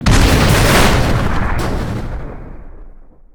barrel explode01.wav